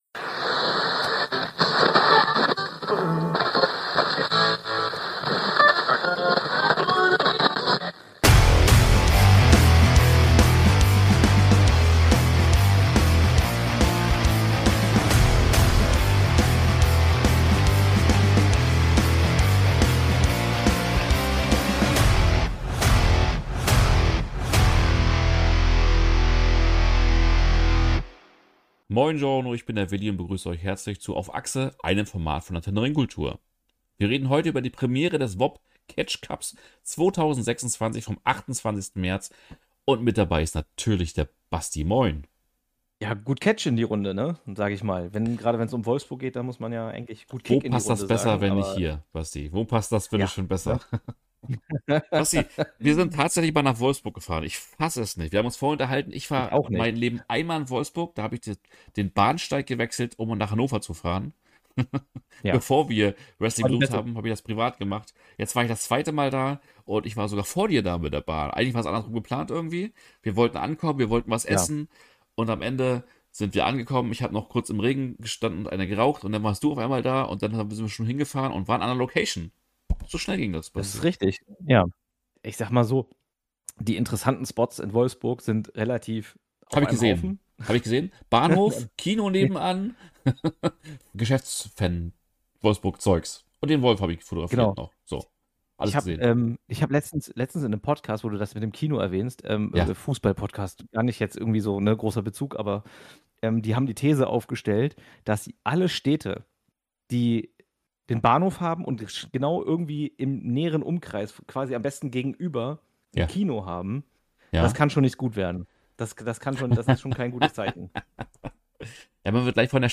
vor Ort um für euch Eindrücke der Veranstaltung zu sammeln. Wer das Ding am Ende gewonnen hat und ob sich eine Reise nach Wolfsburg lohnt, erfahrt ihr nur, wenn ihr reinhört.